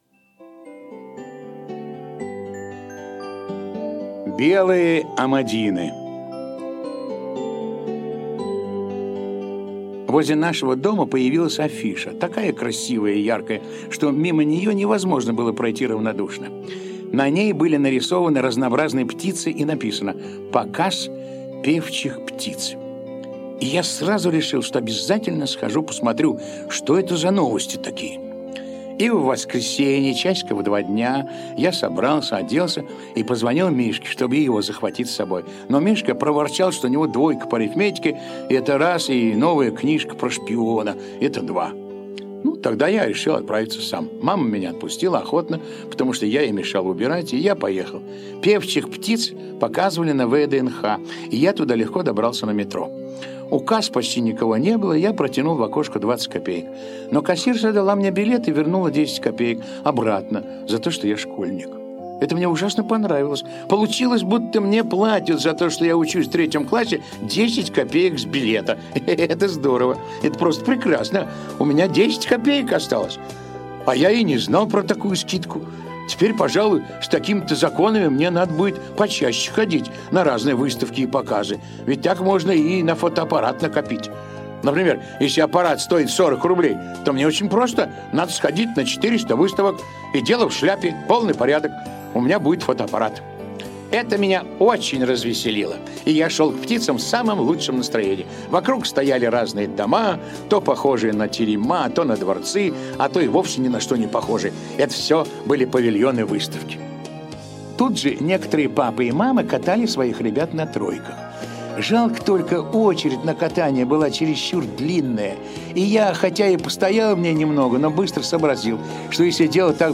Белые амадины - аудио рассказ Драгунского. Рассказ о том, как мальчик Дениска отправился на выставку певчих птиц.